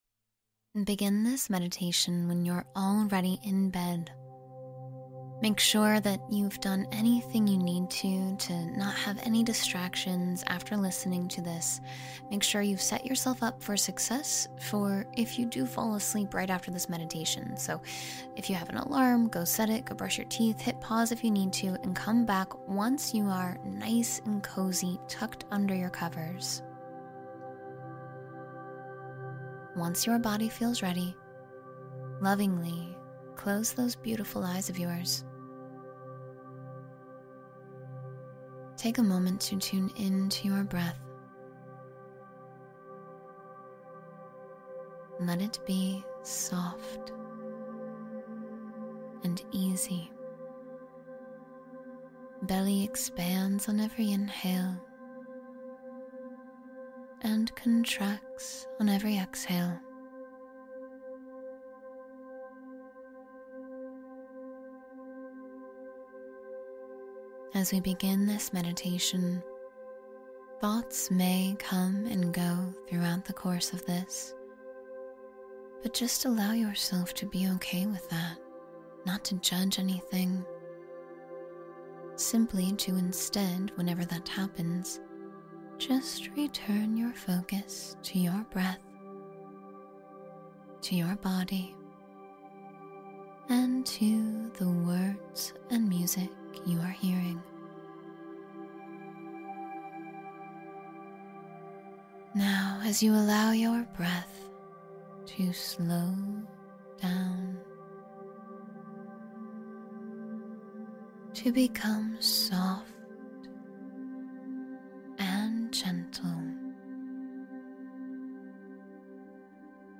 Sleep Meditation — Female Voice for Deep, Restful Sleep